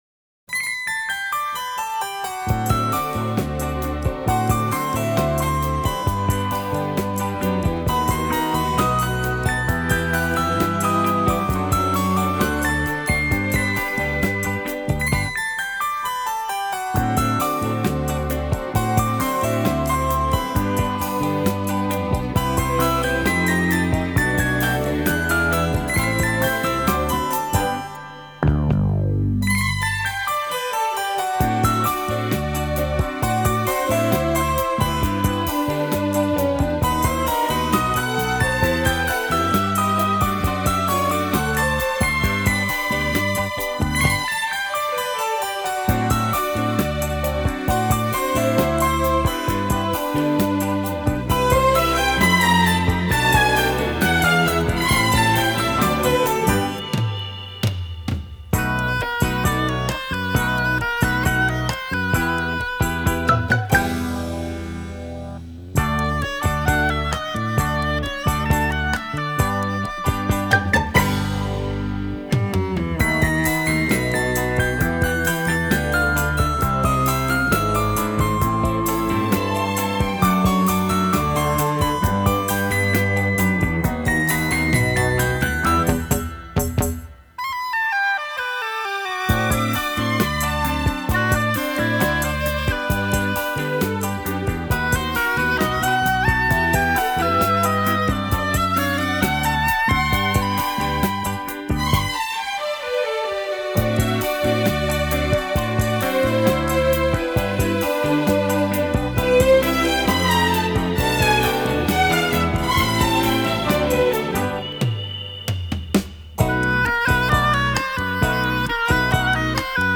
女性をターゲットとしたマーケティングに即して、愛らしく優しいサウンドに仕立てられ、多くの日本人に愛された。